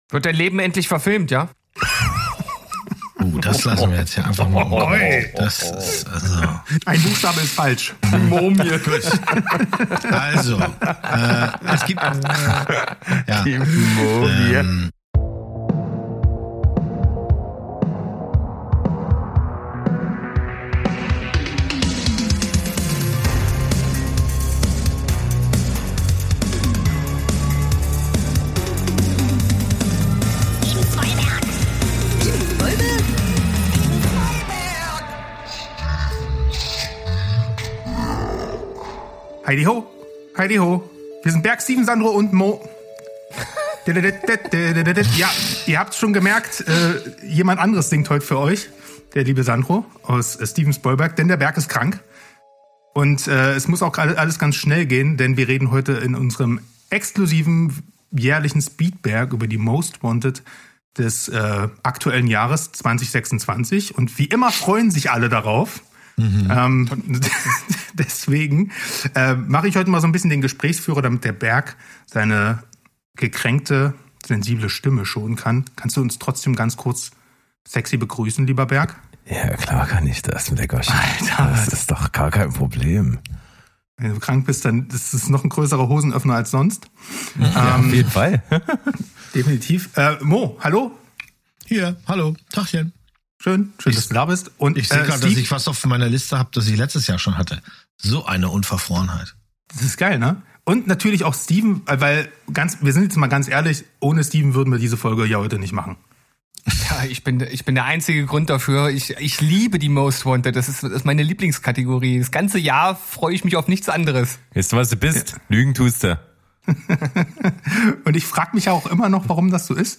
Beschreibung vor 3 Monaten Heidiho Welt, die Spoilvengers steigen erneut mit einem ausufernden Speedberg ins Jahr 2026 ein. Dafür an dieser Stelle umso kürzer: Die vier Dudes pitchen euch die Filme und Serien, auf die sie im laufenden Jahr am meisten Bock haben.